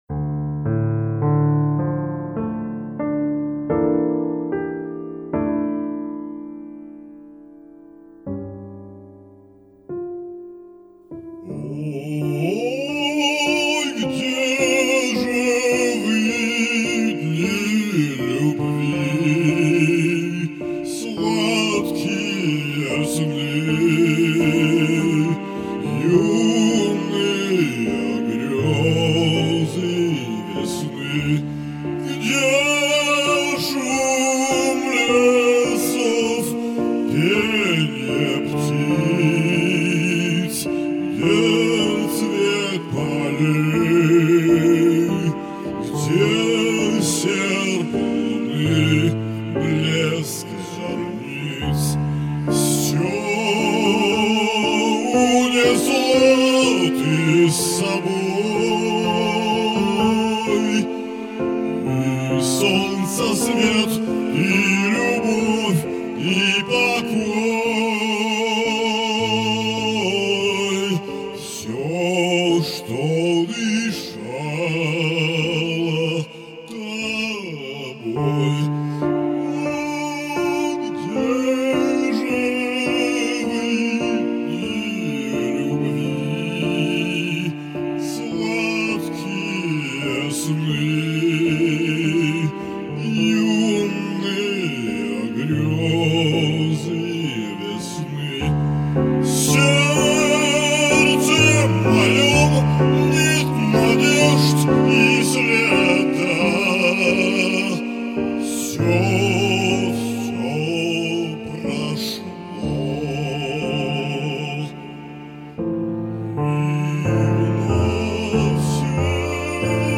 Оба прочтения хороши и прозвучали достойно.